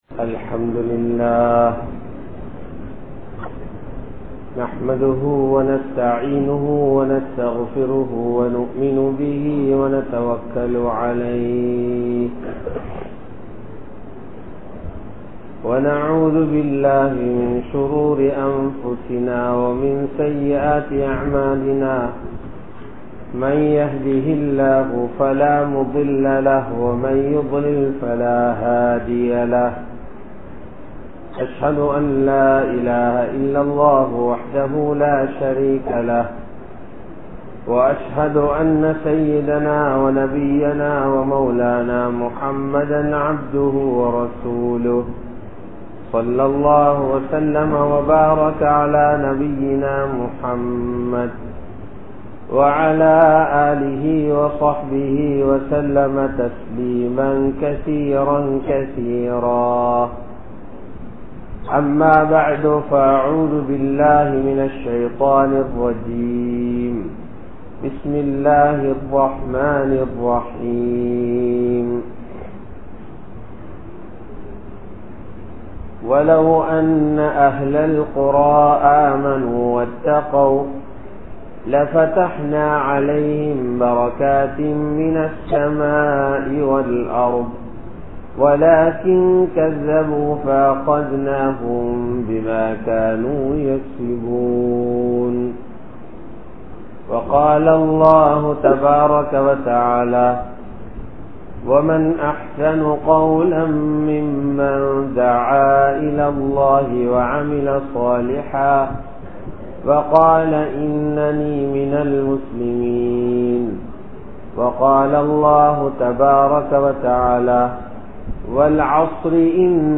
Padaiththa Iraivanai Maranthaal (படைத்த இறைவனை மறந்தால்) | Audio Bayans | All Ceylon Muslim Youth Community | Addalaichenai
Soragoda Jumua Masjidh